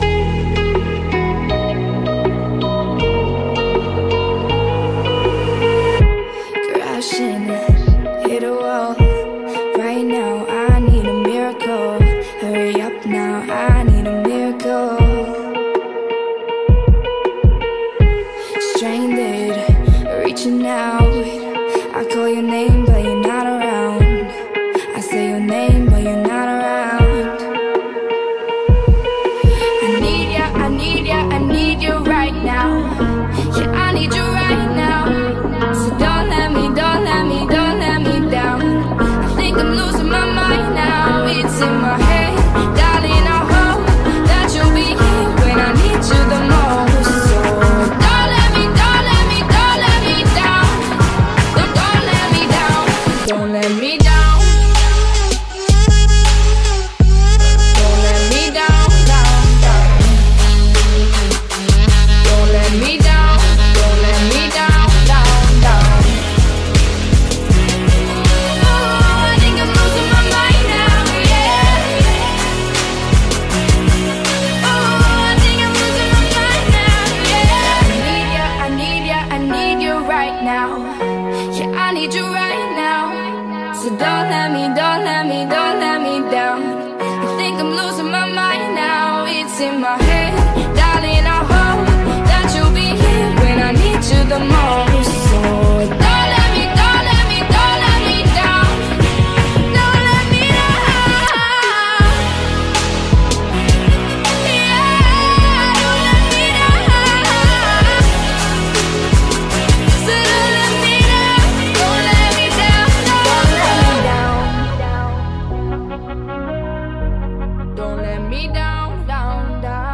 BPM80-160